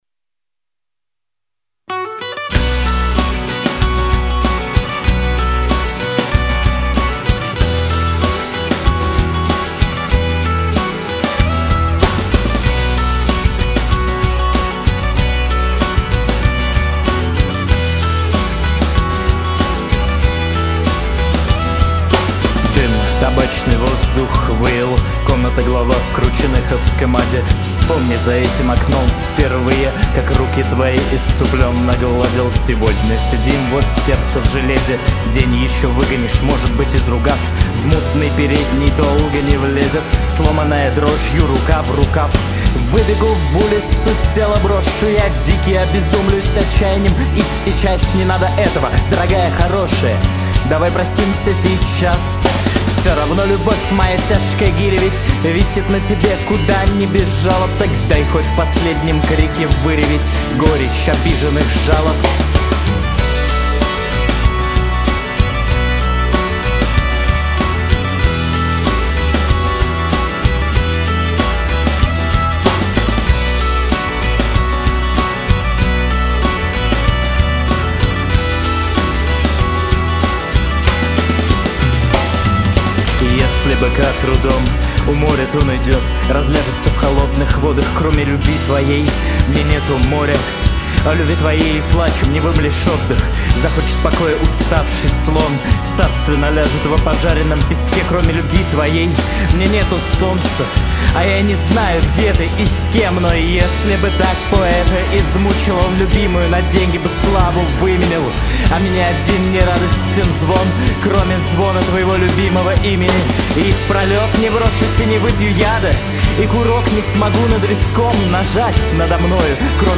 Тоже попробовал шлифануть мп3. Вокалисту,чтобы передать такой текст, пить понемногу не нужно.